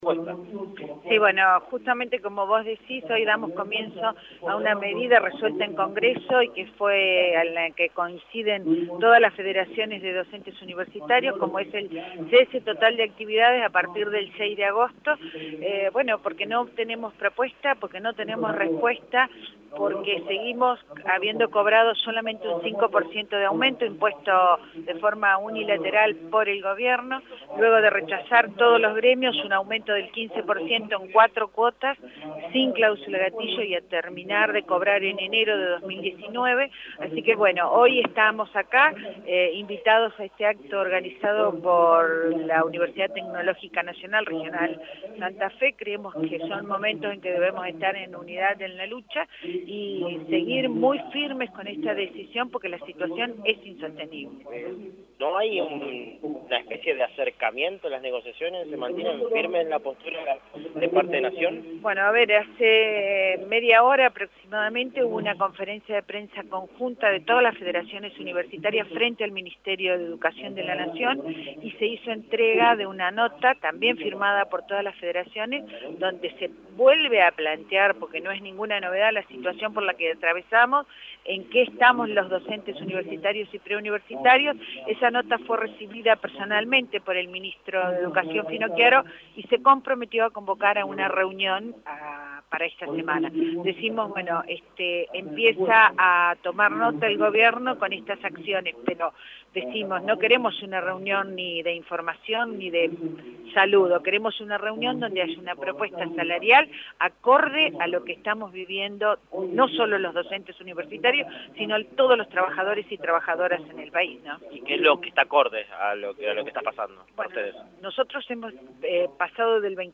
En un acto realizado en la explanada de la UTN